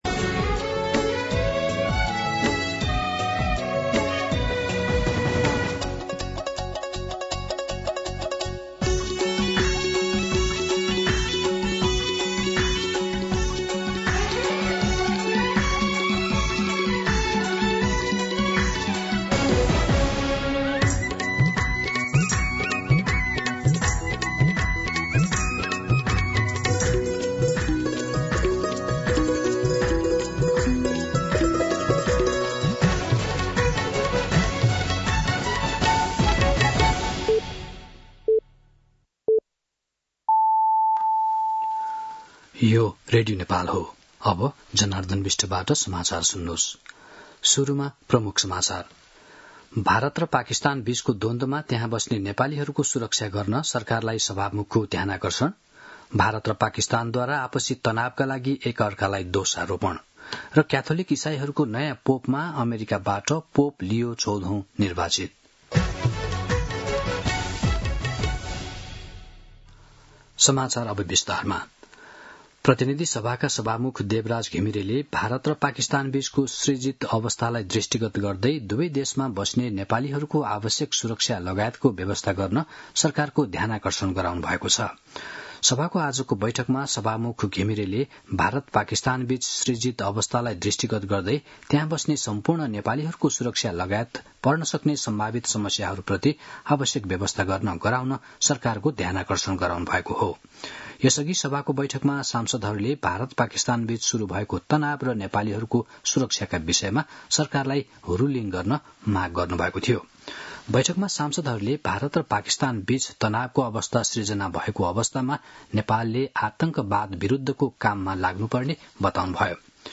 दिउँसो ३ बजेको नेपाली समाचार : २६ वैशाख , २०८२
3pm-News-01-26.mp3